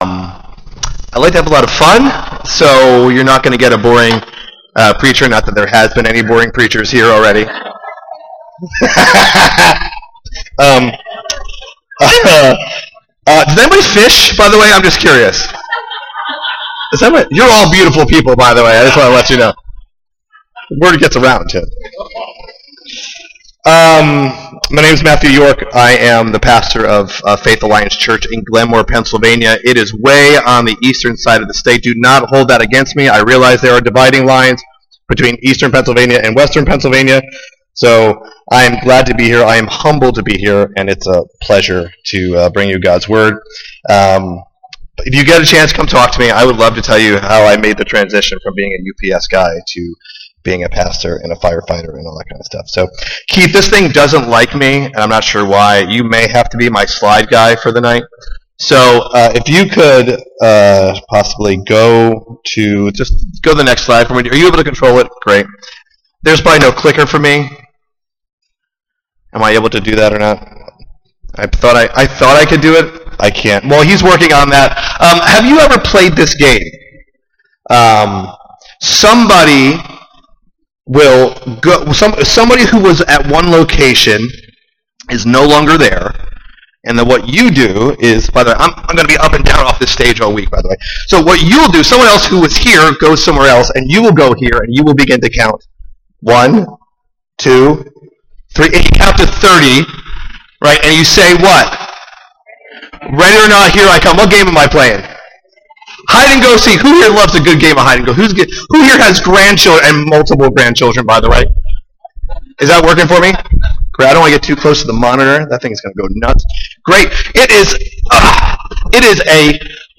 Family Camp 2023